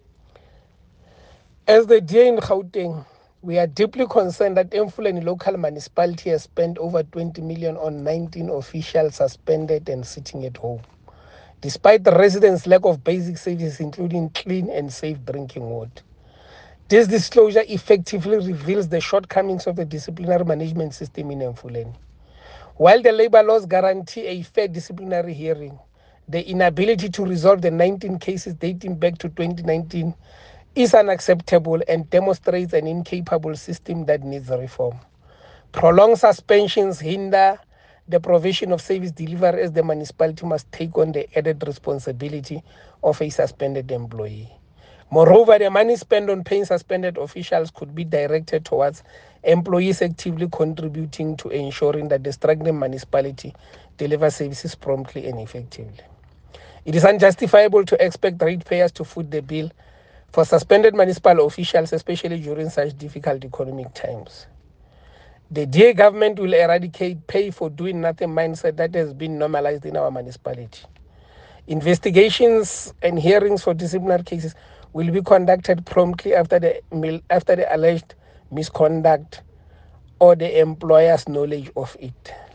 Note to Editors: Please find a soundbite from Kingsol Chabalala in English